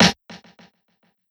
garden shed snare.wav